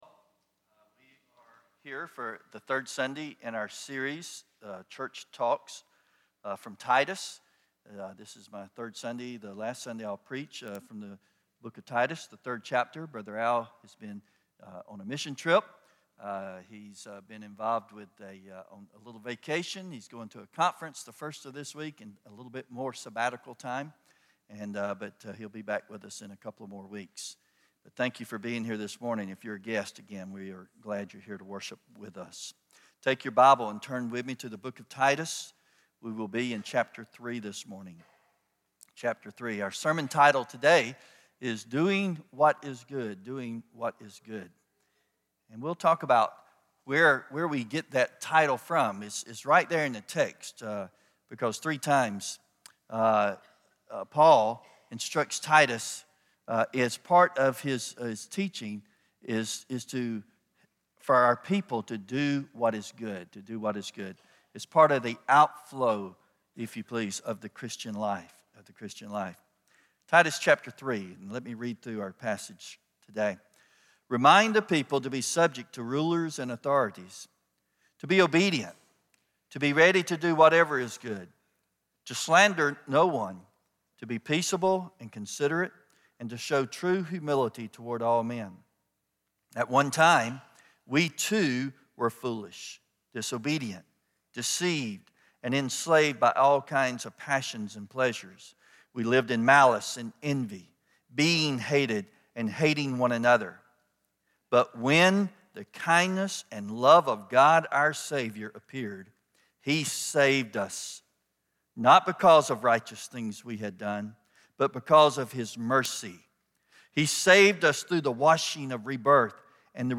Titus 3:1-15 Service Type: Sunday Morning Remember the grace of God that saves us